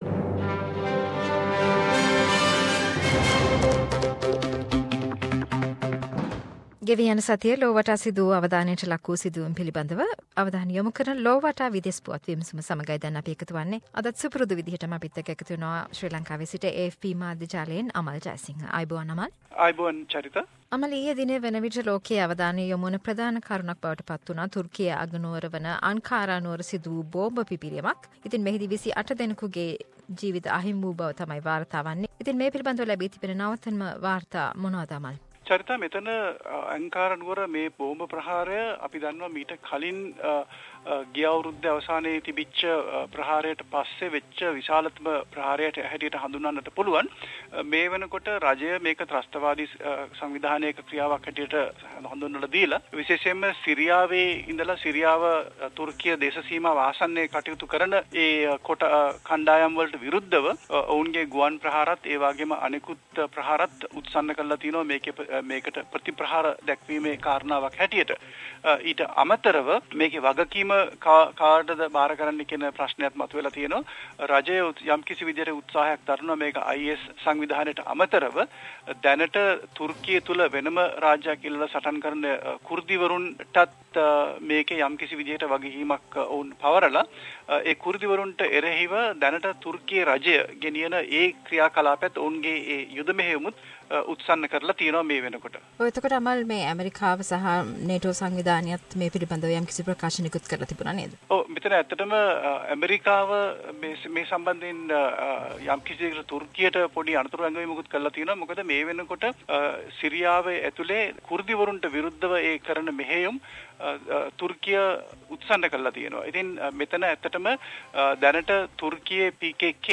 Weekly world news wrap